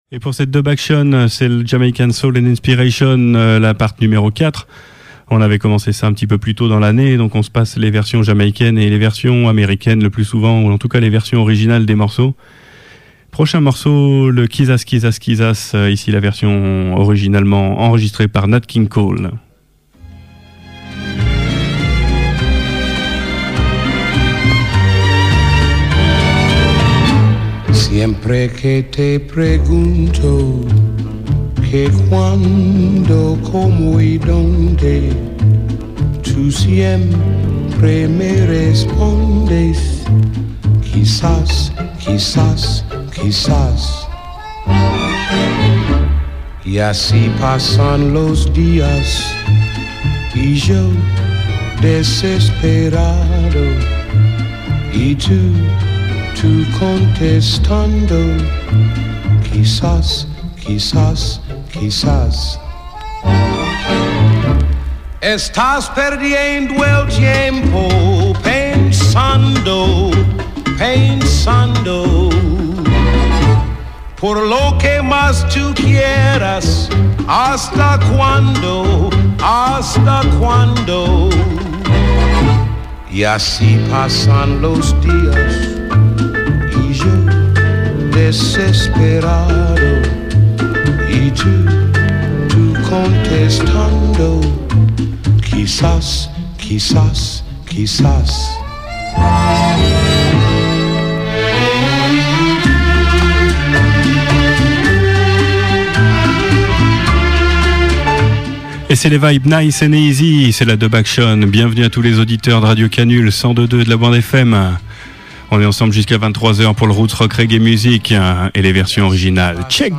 Vinyl selection